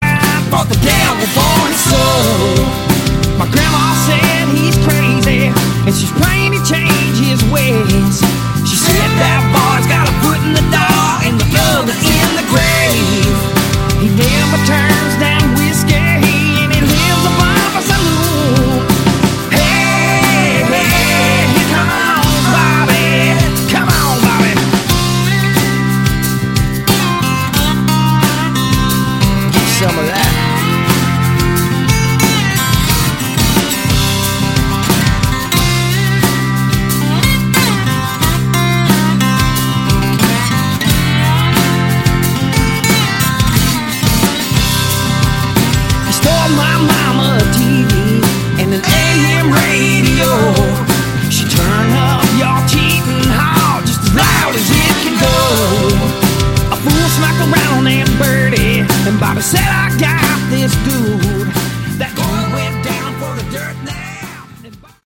Category: Hard Rock
lead vocals, guitars
slide guitar
bass, vocals
drums, percussion